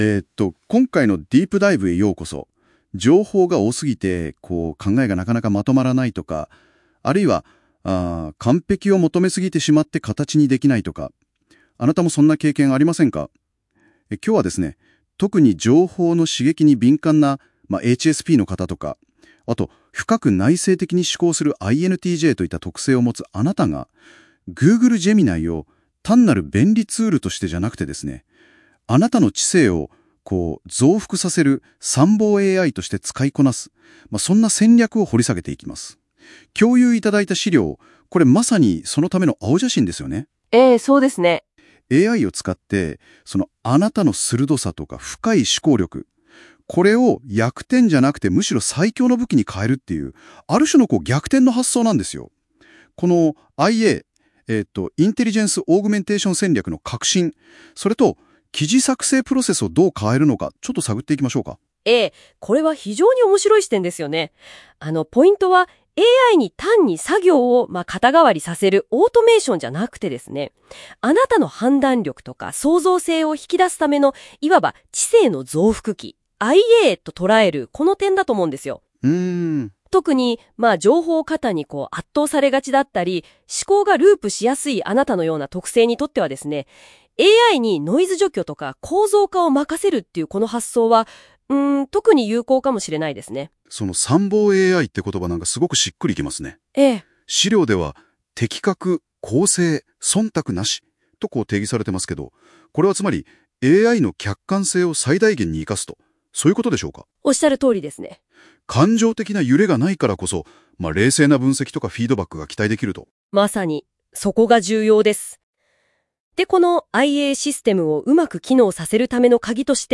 【音声解説】HSPとINTJのための「参謀AI」戦略：認知的摩擦で知性を増幅し、情報過多を最強の武器に変える方法
音声解説を追加。